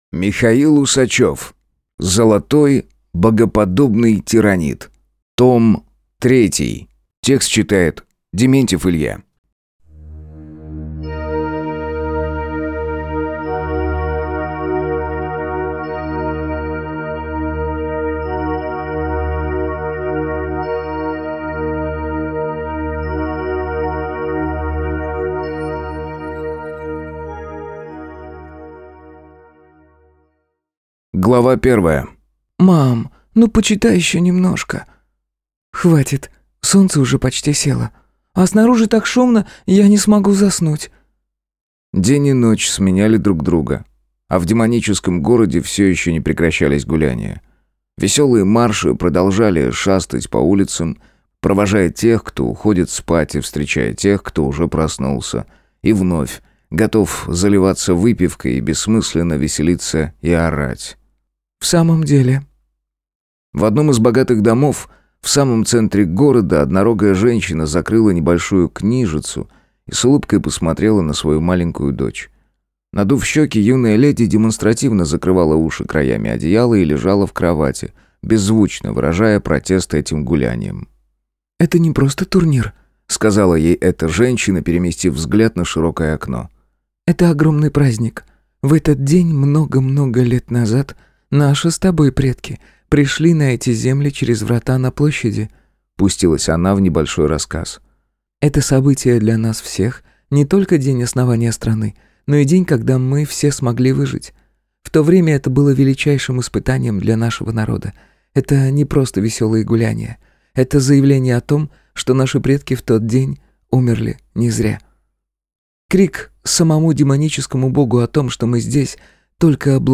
Аудиокнига Золотой Богоподобный Тиранид (Том 3) | Библиотека аудиокниг